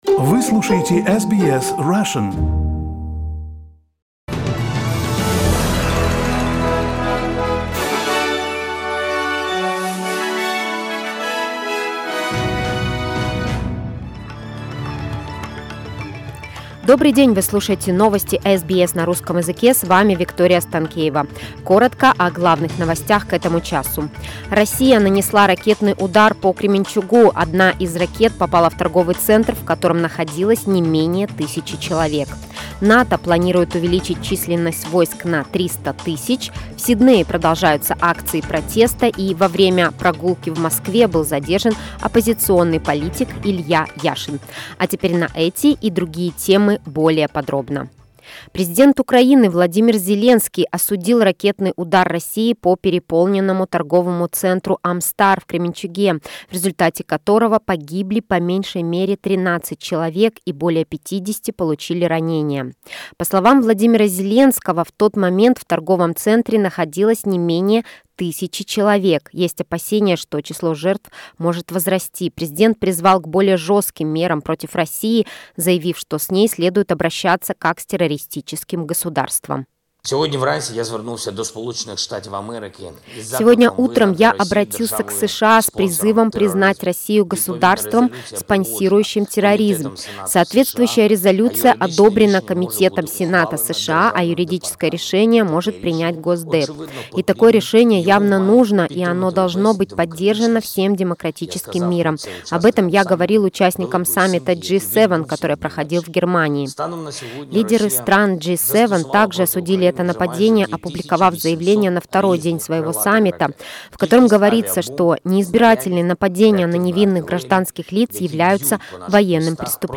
SBS news in Russian — 28.06.22